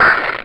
boss_hurt.wav